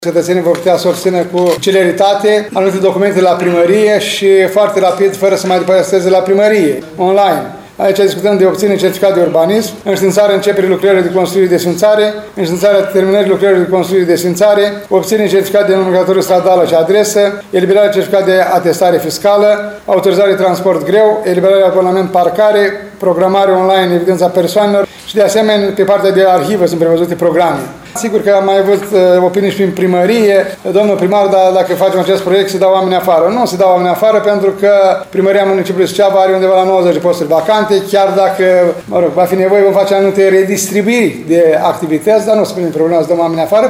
Aceasta este opinia primarului ION LUNGU, care a declarat că – dacă situația o va impune – se va face o redistribuire a angajaților.